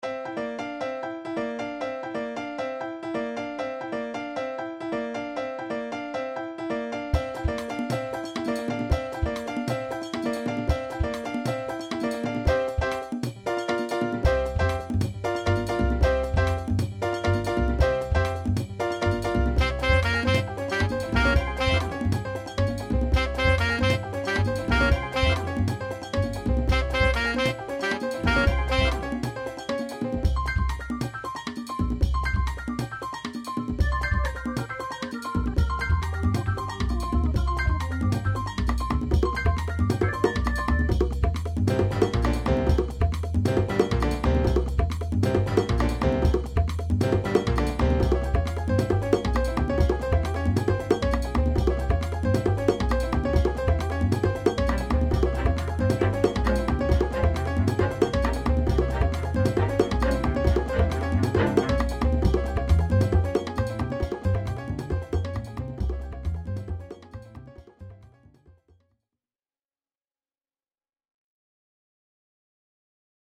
allez encore un petit bout puis stop...ceci dit les percus sont parfaites....